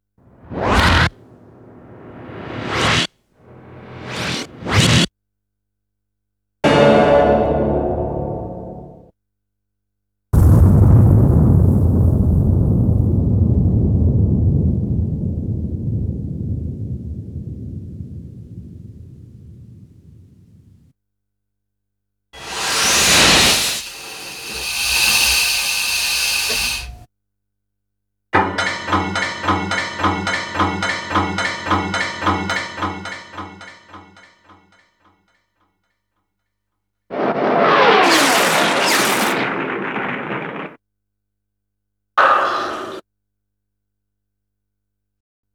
39 Sound Effects.wav